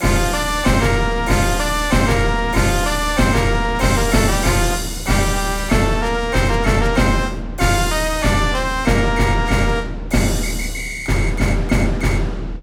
FIGHT SONG